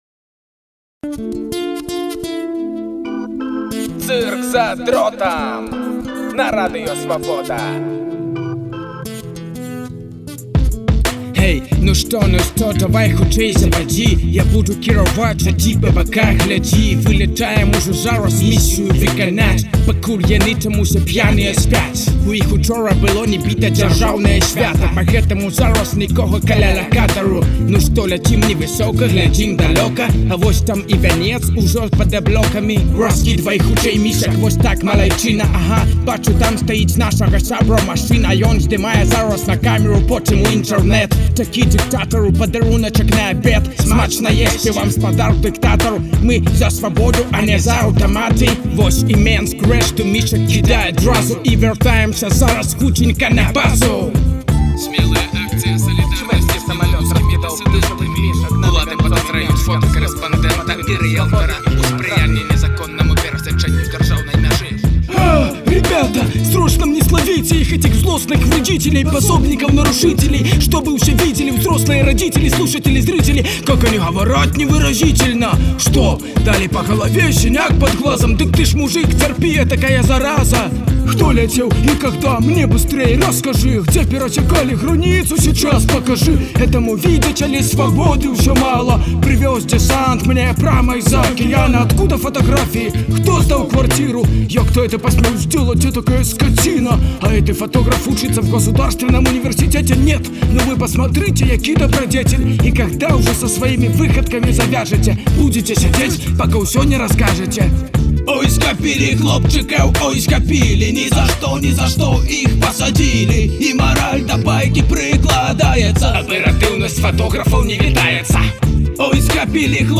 сатырычная праграма